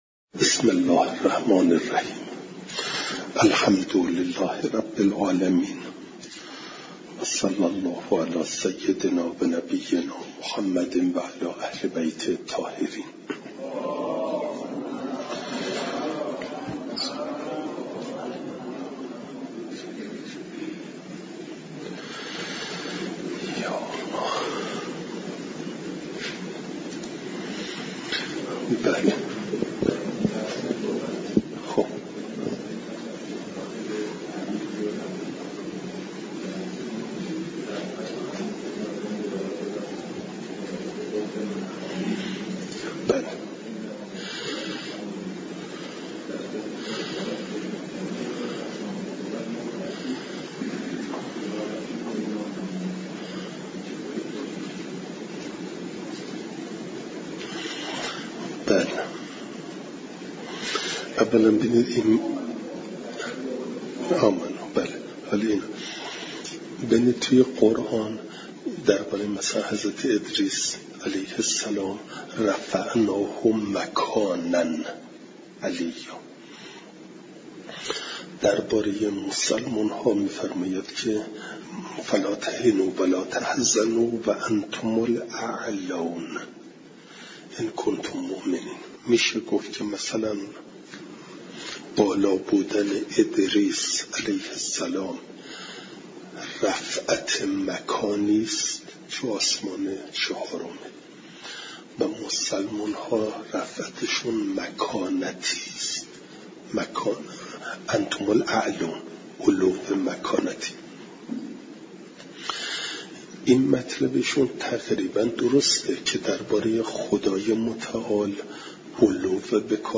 فایل صوتی جلسه دویست و سی و یکم درس تفسیر مجمع البیان